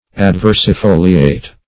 Search Result for " adversifoliate" : The Collaborative International Dictionary of English v.0.48: Adversifoliate \Ad*ver`si*fo"li*ate\, Adversifolious \Ad*ver`si*fo"li*ous\a. [L. adversus opposite + folium leaf.]
adversifoliate.mp3